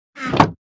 Minecraft Version Minecraft Version latest Latest Release | Latest Snapshot latest / assets / minecraft / sounds / block / chest / close3.ogg Compare With Compare With Latest Release | Latest Snapshot